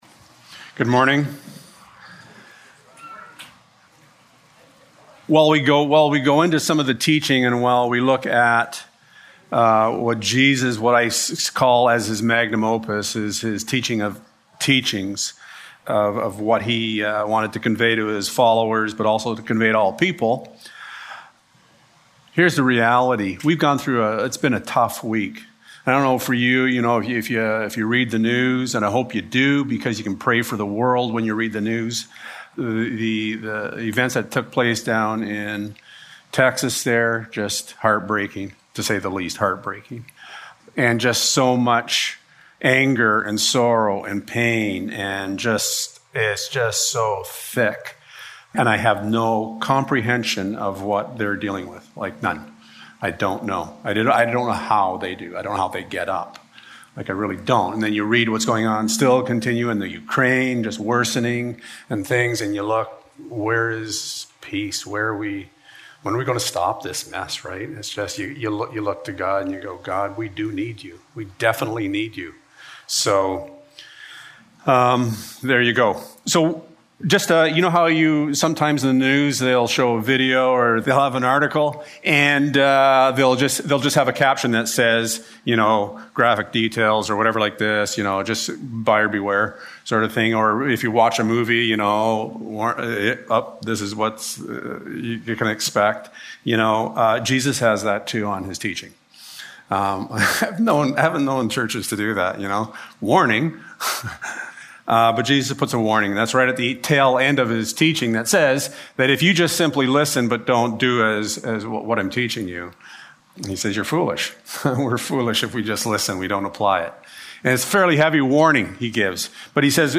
1-4 Service Type: Sunday Morning Have you ever wondered what it is that moves you to do the things that you do?